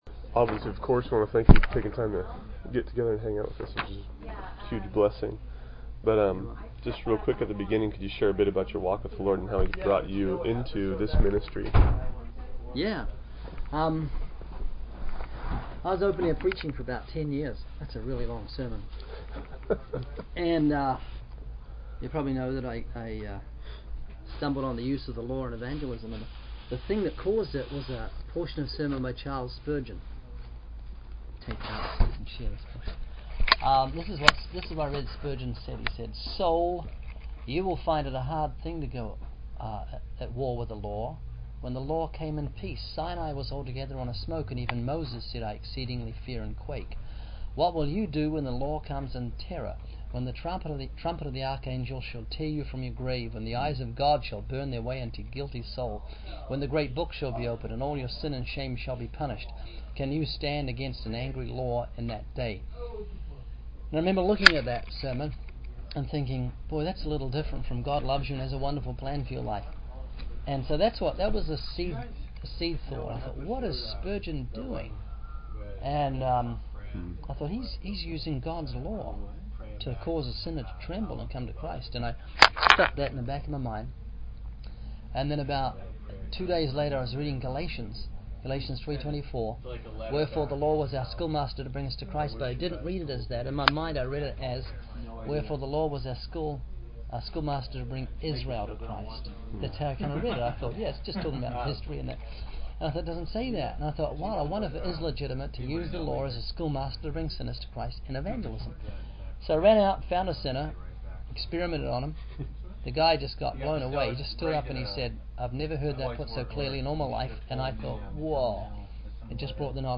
In this video, the speaker begins by expressing gratitude for the opportunity to share and discuss their ministry. They then share their personal journey of being led by God into preaching and how they discovered the importance of using the law in evangelism.